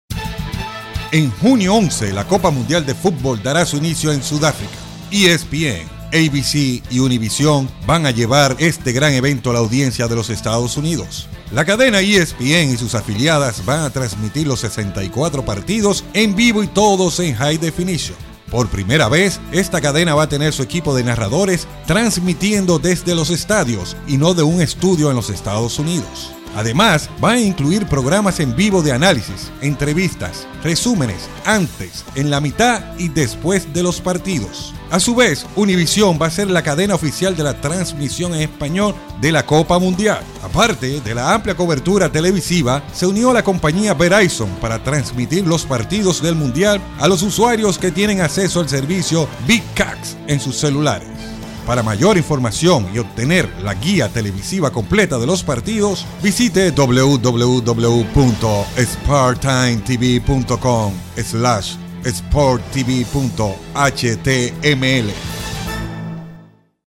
Hispano parlante, excelente para voz en off con matices de animador y narrador.
spanisch Südamerika
Sprechprobe: Werbung (Muttersprache):